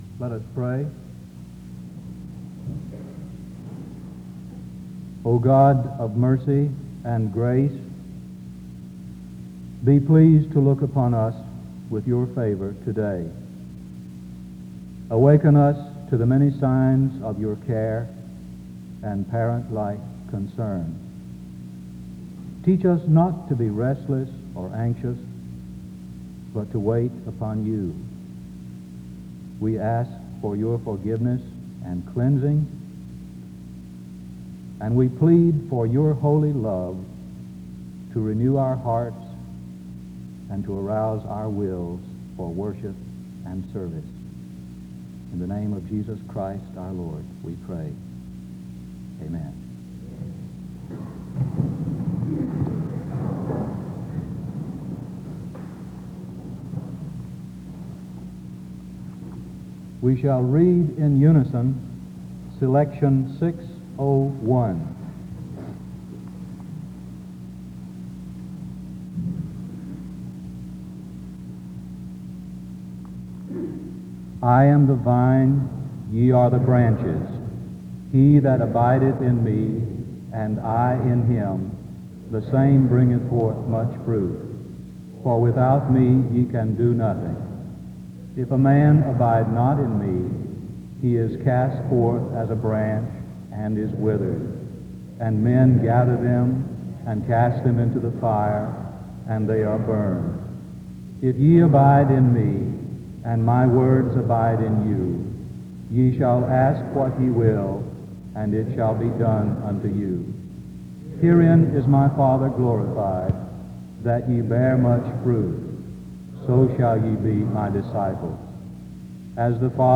A responsive reading takes place from 1:00-2:40.